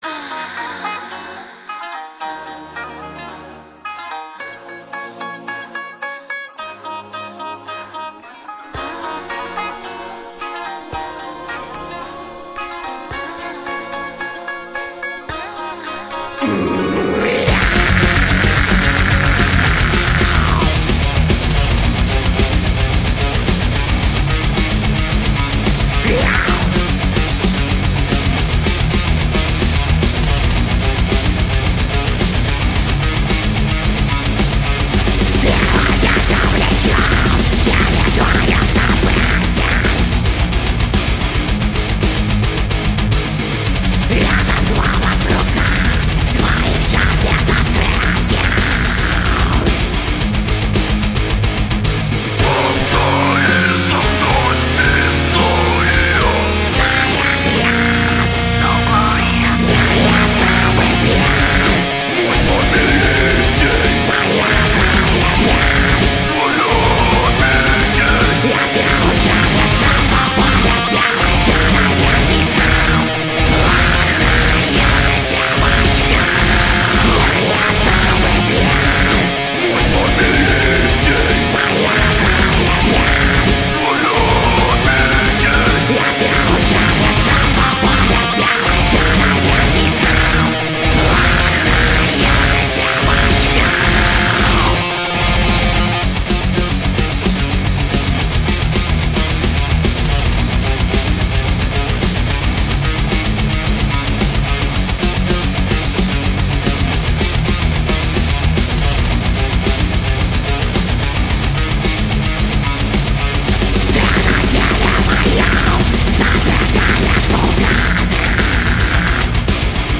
Кавер-версия, в исполнении злобных альтернативщиков.